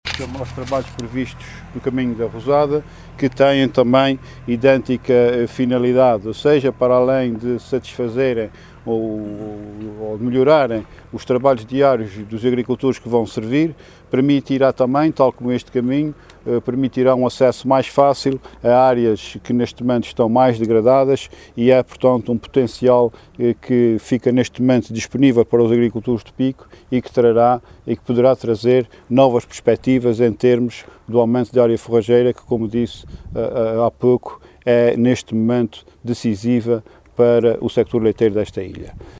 “O Governo dos Açores mantém o seu compromisso de liquidar, até ao final do ano, a primeira tranche da linha de compensação financeira no âmbito do SAFIAGRI a todos os agricultores beneficiários”, afirmou Luís Neto Viveiros, em declarações à margem da inauguração do Caminho Rural do Vitorino, no concelho das Lajes do Pico.